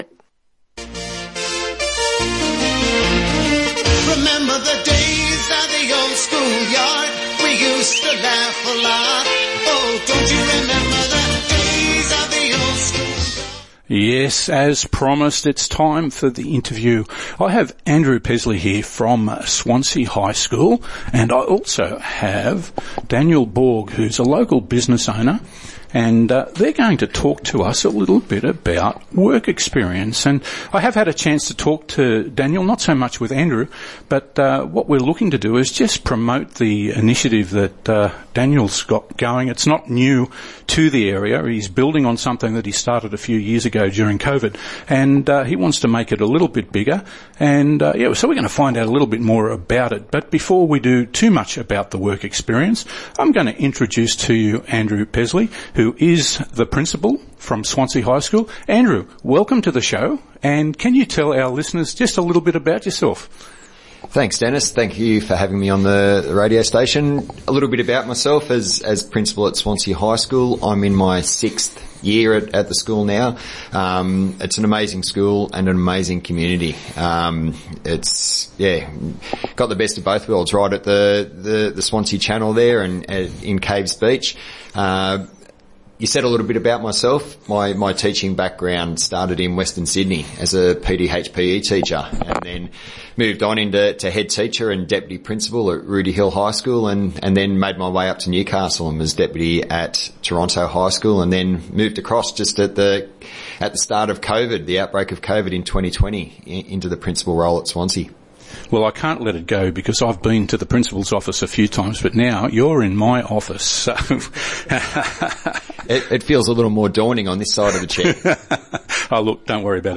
Caves Connect and Swansea High School team up to launch Work Experience Connect — a local initiative helping students gain real-world experience and strengthening business-community ties. Listen to the Lake Macquarie FM interview.